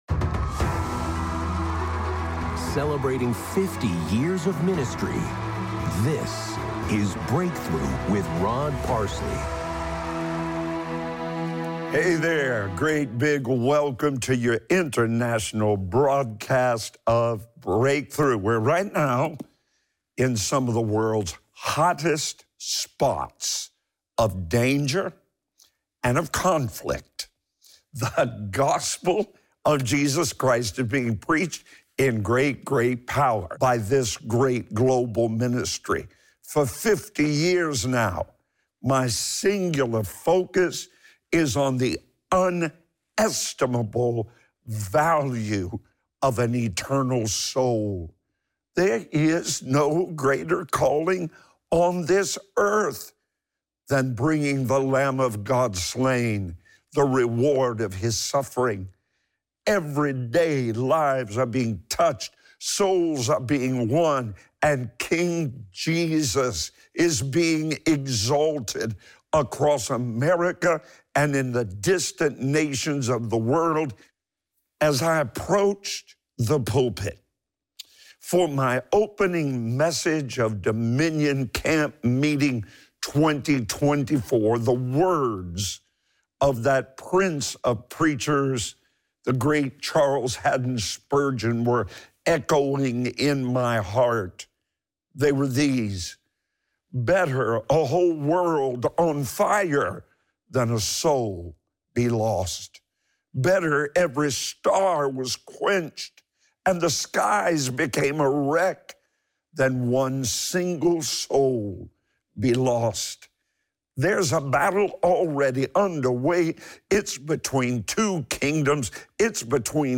Audio only from the daily television program Breakthrough